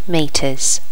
Normalize all wav files to the same volume level.
meters.wav